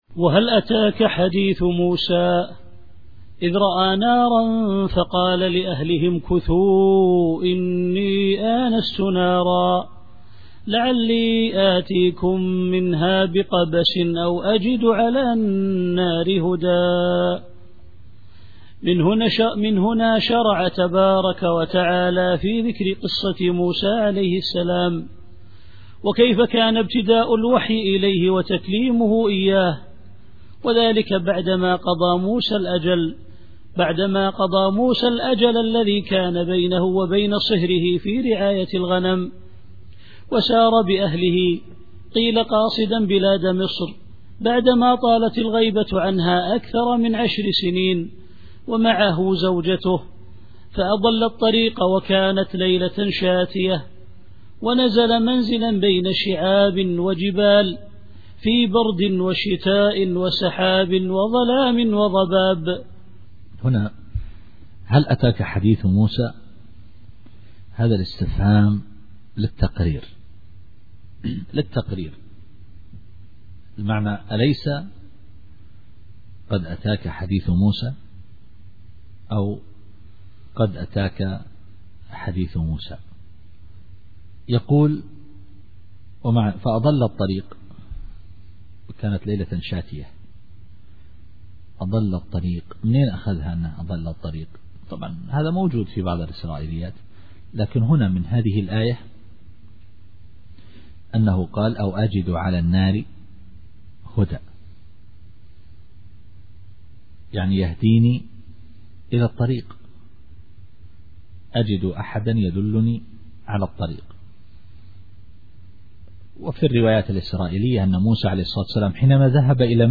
التفسير الصوتي [طه / 9]